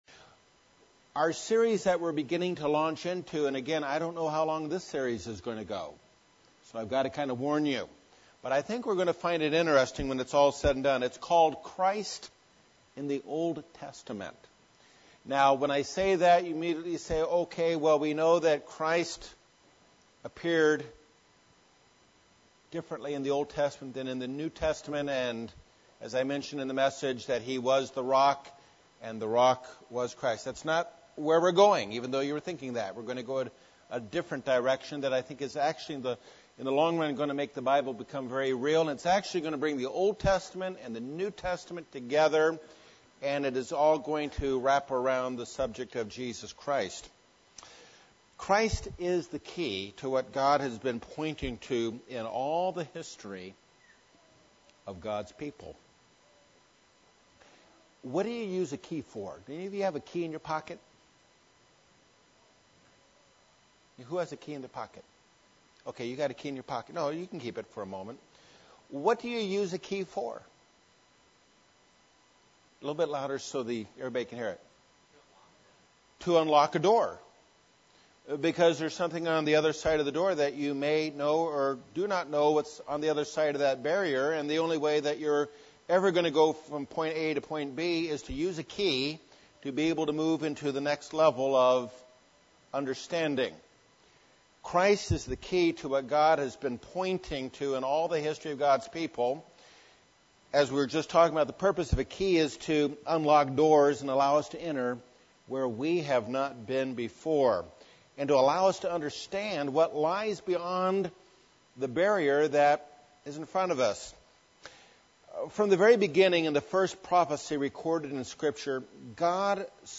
The beginning of a Bible study on types and anti-types of Christ in the Old Testament.